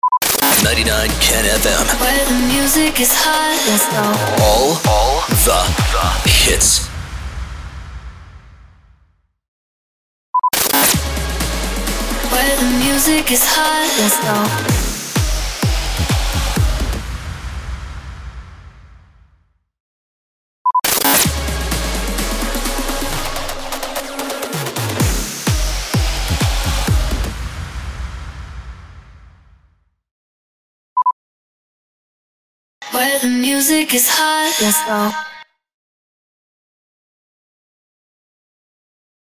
703 – SWEEPER – WHERE THE MUSIC IS HOT
703-SWEEPER-WHERE-THE-MUSIC-IS-HOT.mp3